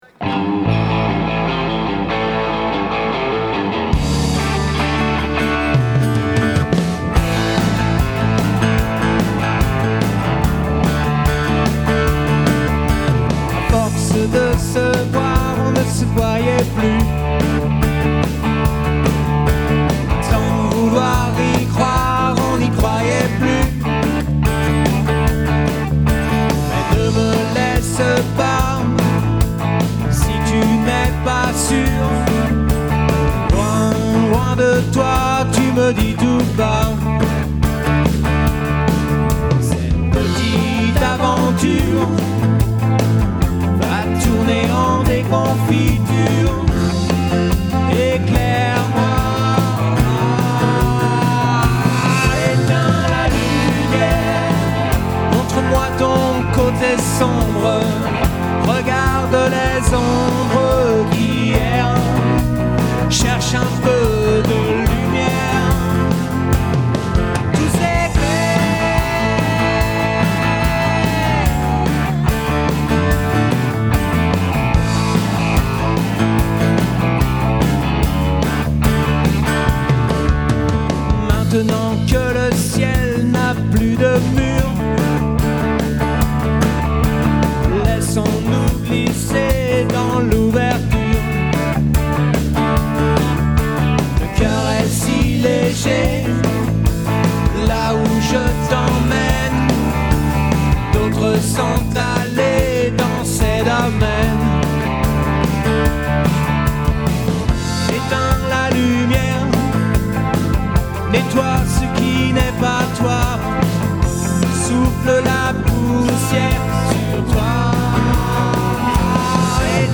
Programme d'orchestre de variété avec chanteuse et chanteur
Batterie, Chant & Guitare
Piano & Clavier